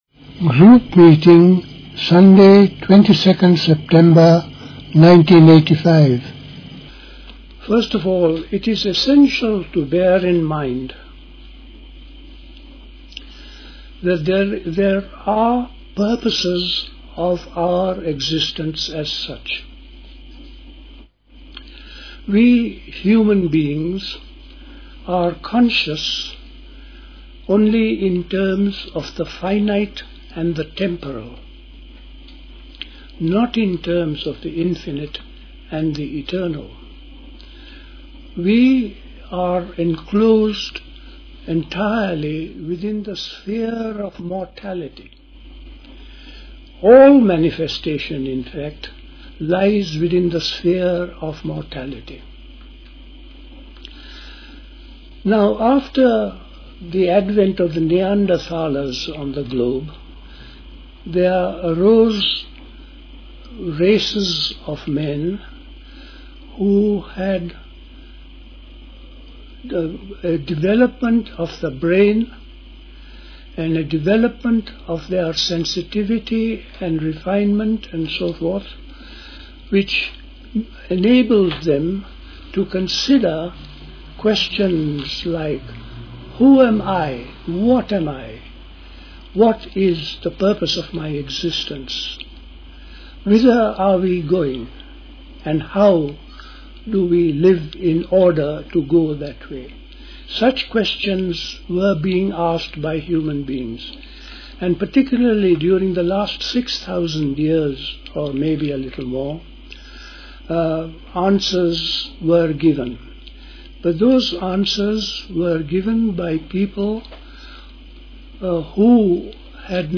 at Dilkusha, Forest Hill, London on 22nd September 1985
Talk